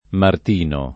mart&no] pers. m. — tronc. in Martin Lutero, Martin Polono, e nel proverbio per un punto Martin perdé (o perse) la cappa; tronc., con m- minusc., in martin pescatore [mart&m peSkat1re] e martin secco [martin S%kko] (locuzioni dove il nome proprio si è fatto nome comune, e dove d’altra parte il tronc. riproduce un’originaria forma francese) — sim. i cogn.